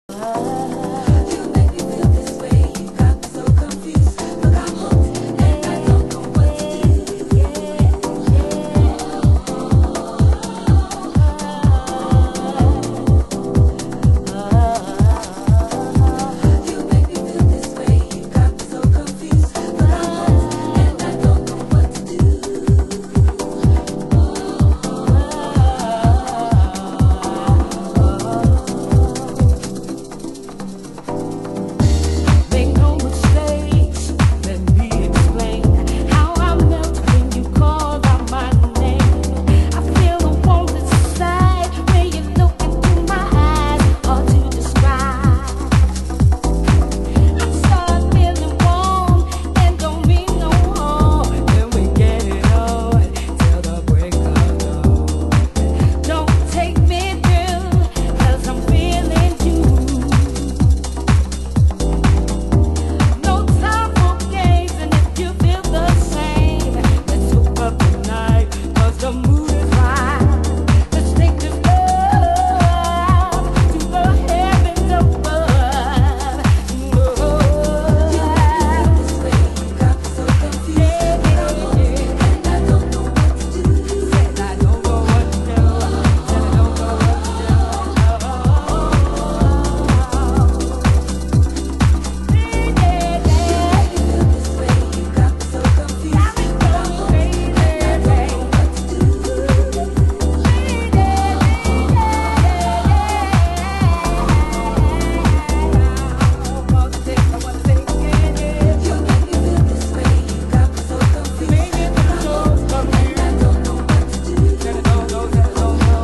盤質：スレ傷、小傷、少しチリパチノイズ有